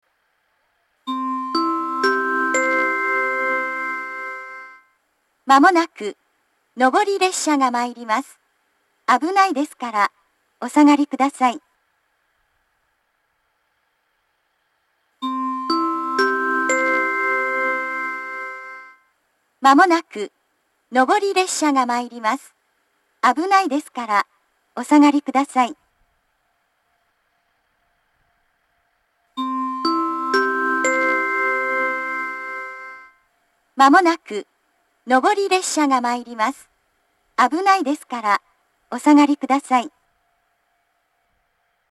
２番線接近放送
sansai-2bannsenn-sekkinn.mp3